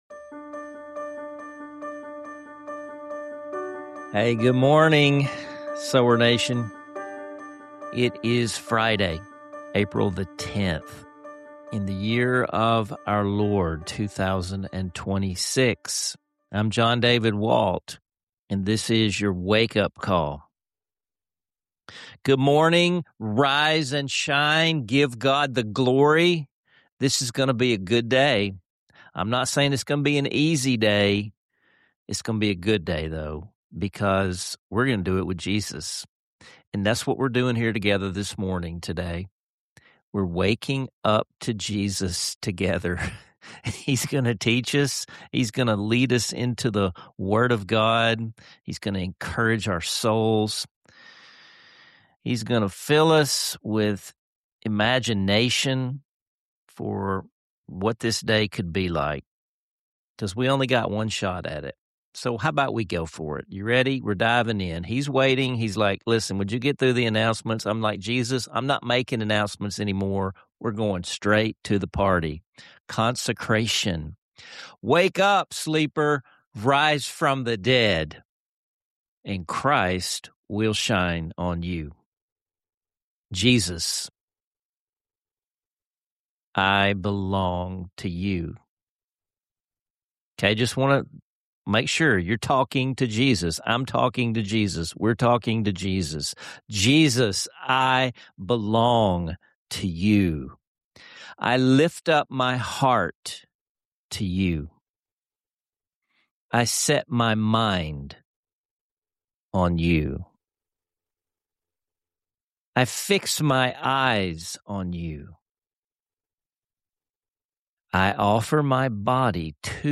Stick around for a heartfelt musical prayer—“Open My Eyes, Lord”—prompting us to ask God for new vision and openness to His presence every day.